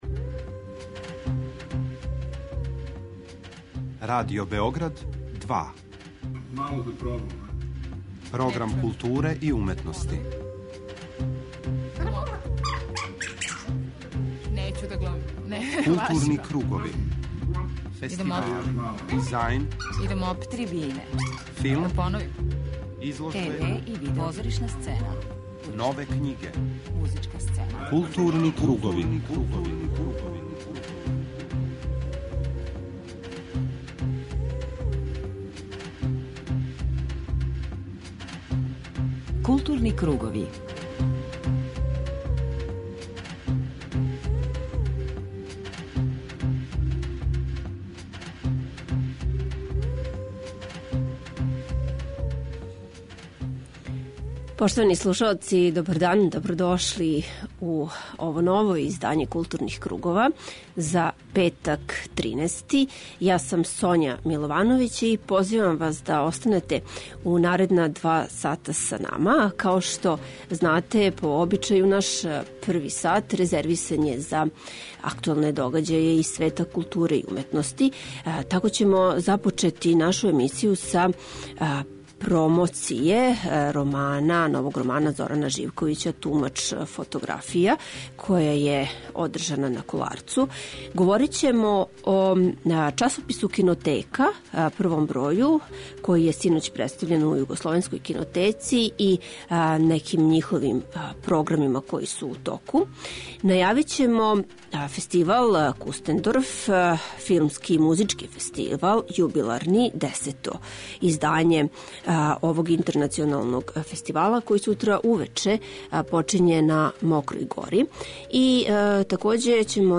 У Гутенберговом одговору , чућете разговоре са овим писцима.